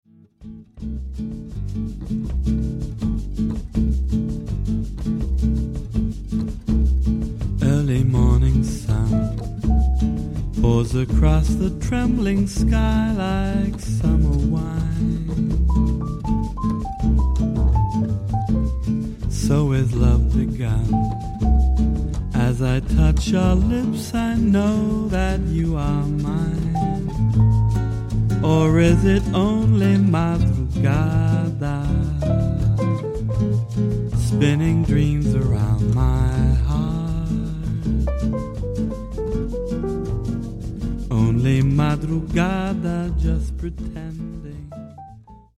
飾らないサウンドとなでるような歌声に気持ちをほだされる、粋で洒落たボッサ＆ジャジーな夜に、あなたは何を想う…？